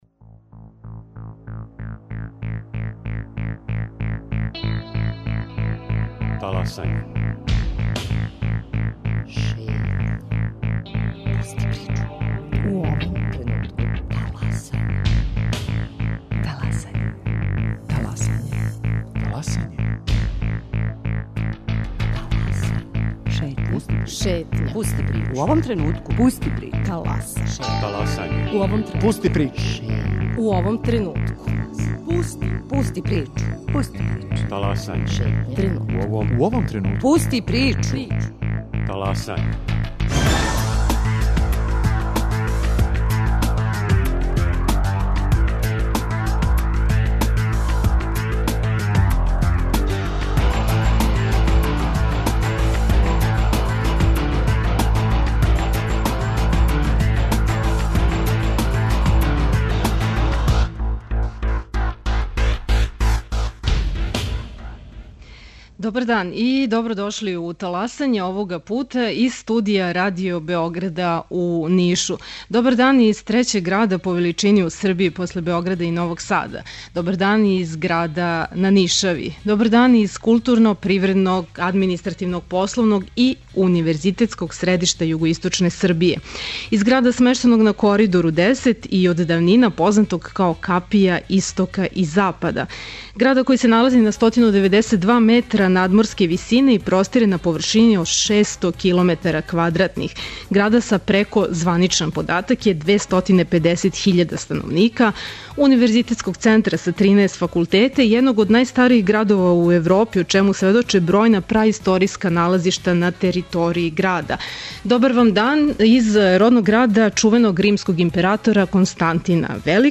Чућете је с лица места, јер Таласање овога понедељка емитујемо из студија Радио Београда у Нишу.